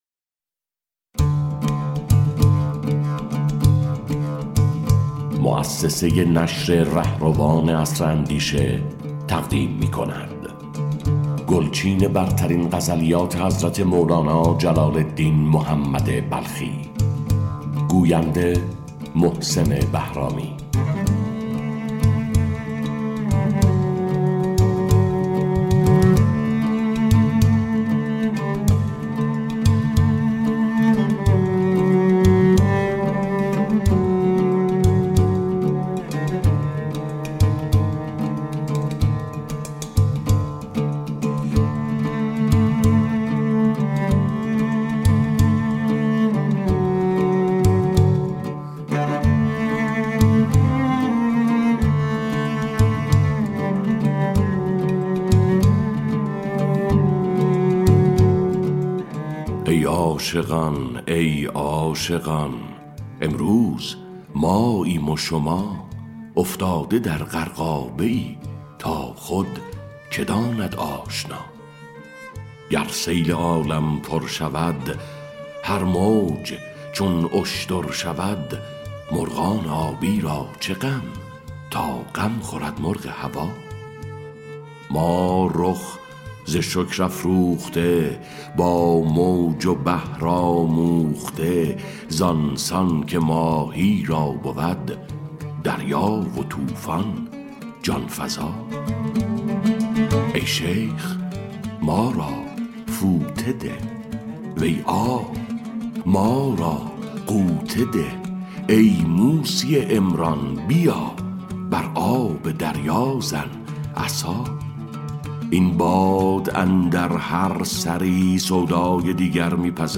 کتاب صوتی گزیده غزلیات مولانا جلال‌الدین محمد بلخی اثر مولانا